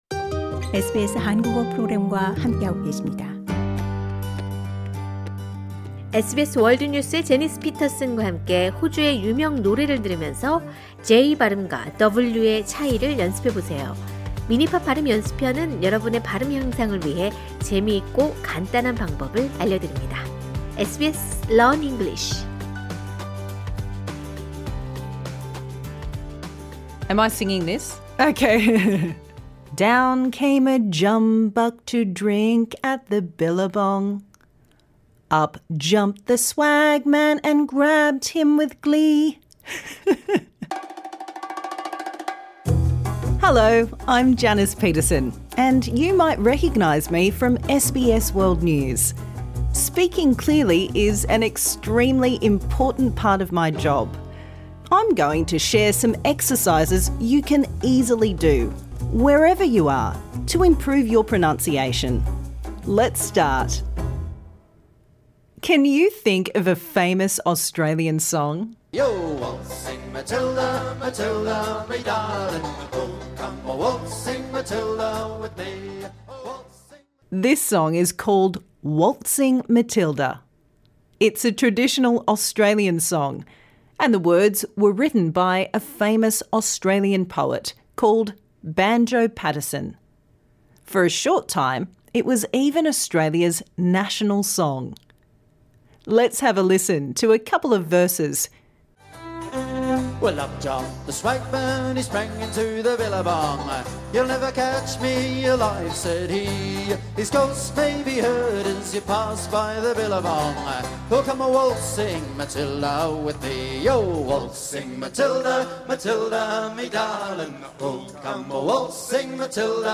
This lesson suits all learners at all levels.